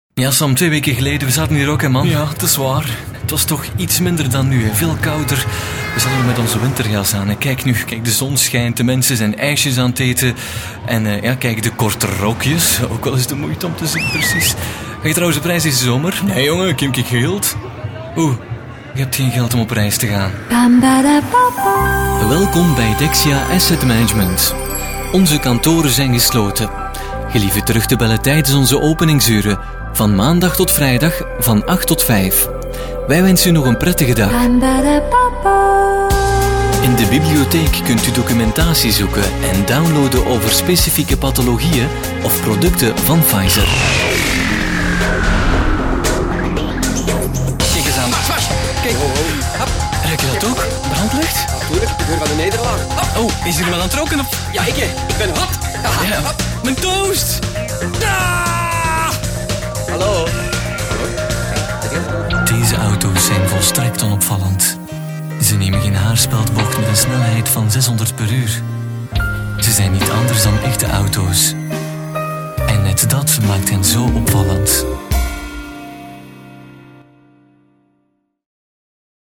Sprecher flämisch
Sprechprobe: Werbung (Muttersprache):
flemish voice over artist